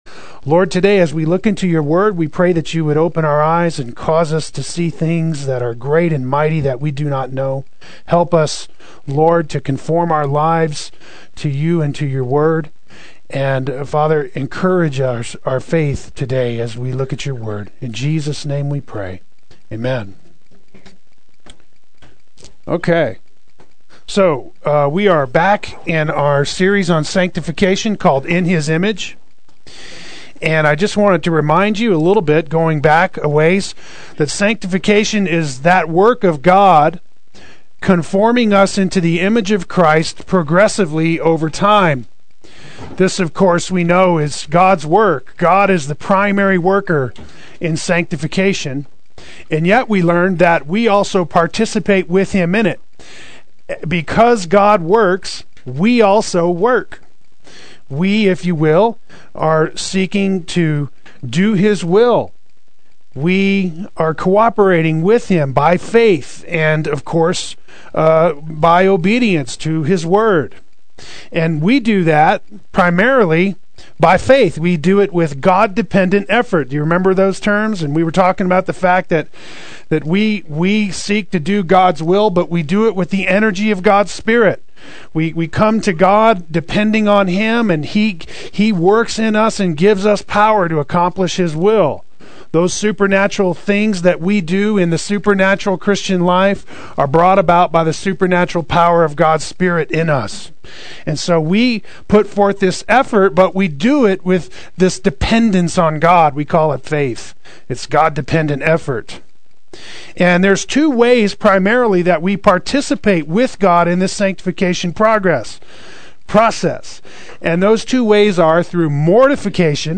Play Sermon Get HCF Teaching Automatically.
Cultivating Faith Adult Sunday School